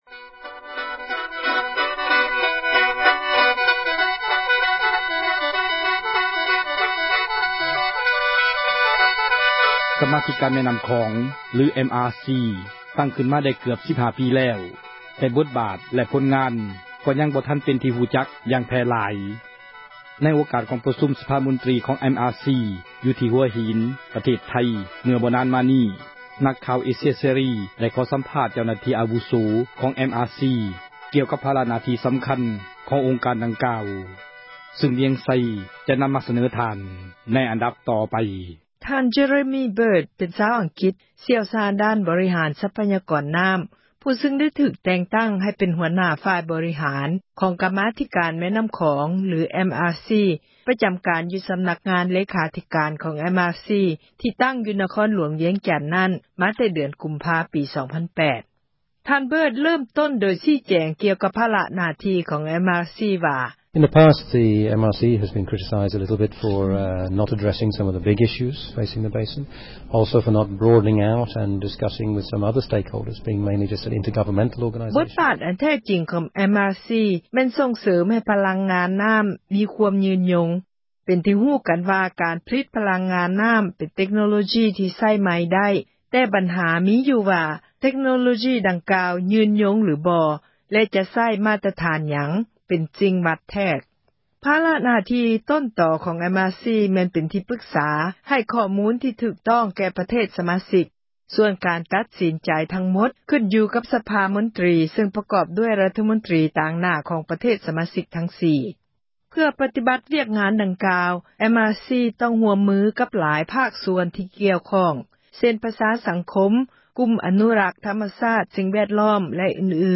ກັມມາທິການແມ່ນ້ຳຂອງ ຫລື MRC ຕັ້ງຂຶ້ນມາໄດ້ເກືອບ 15 ປີແລ້ວ ແຕ່ບົດບາດແລະຜົນງານ ກໍຍັງບໍ່ເປັນທີ່ ຮູ້ຈັກຢ່າງແຜ່ຫລາຍ. ໃນໂອກາດກອງປະຊຸມ ສະພາມົນຕຣີຂອງ MRC ຢູ່ທີ່ຫົວຫີນ ປະເທດໄທ ເມື່ອບໍ່ນານມານີ້ ນັກຂ່າວເອເຊັຍເສຣີ ໄດ້ຂໍສຳພາດ ເຈົ້າໜ້າທີ່ອາວຸໂສ ຂອງ MRC ກ່ຽວກັບພາລະ ໜ້າທີ່ສຳຄັນຂອງ ອົງການດັ່ງກ່າວ.